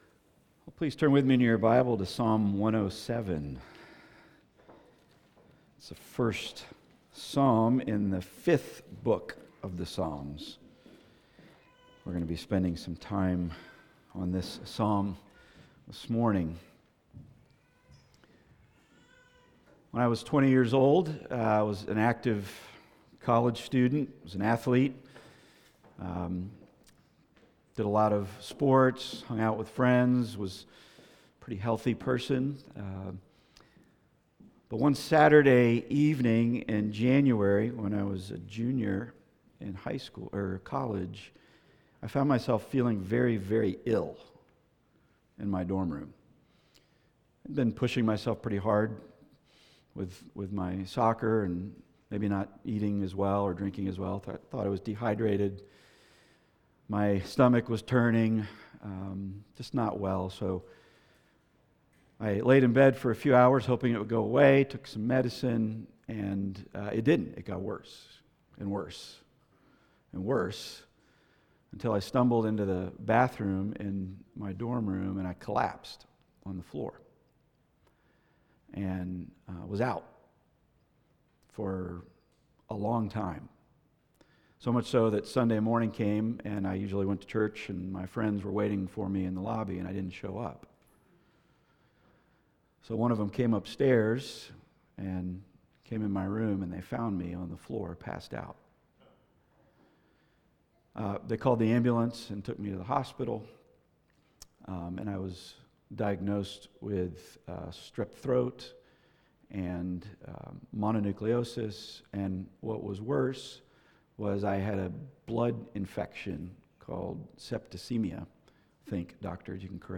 Passage: Psalm 107 Service Type: Weekly Sunday